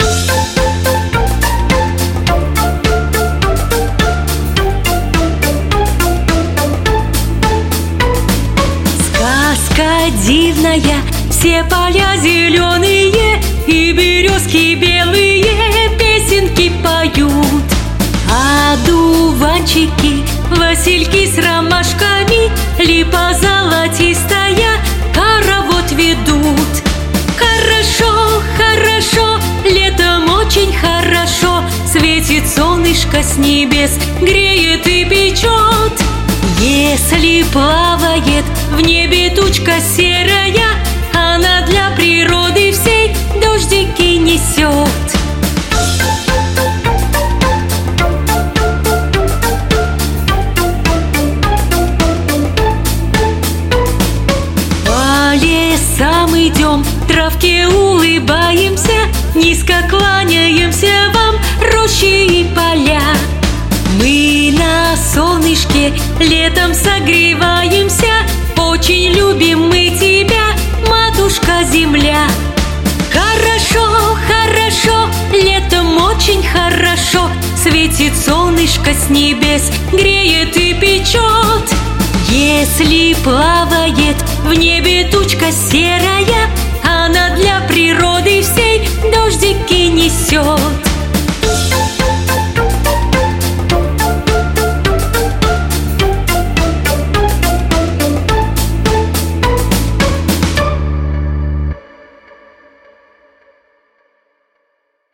🎶 Детские песни / Времена года / Песни про Лето 🌻